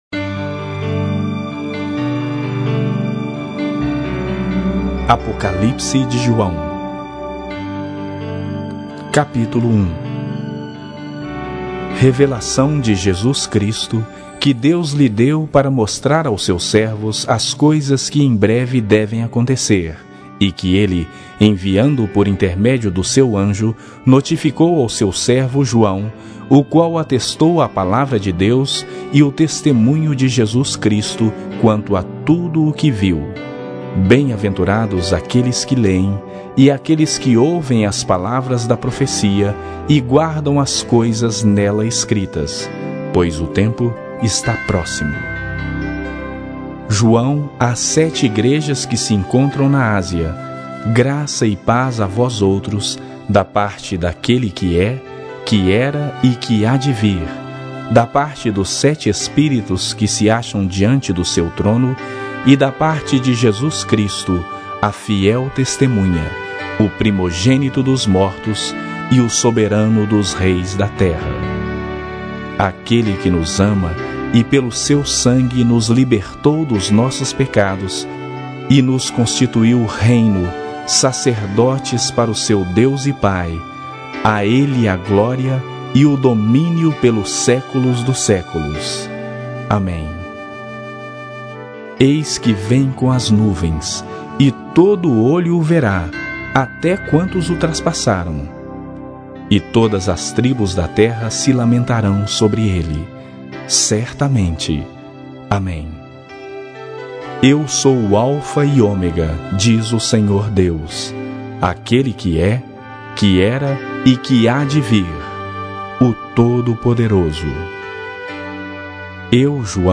Bíblia Sagrada Online Falada
Versão Bíblia Falada Ministério (?)